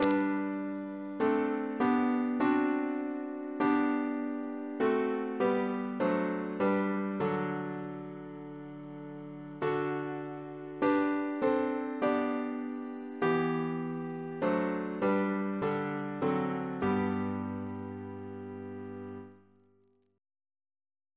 Double chant in G Composer: William Crotch (1775-1847), First Principal of the Royal Academy of Music Reference psalters: H1940: 637; H1982: S429